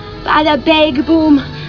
bigboom.wav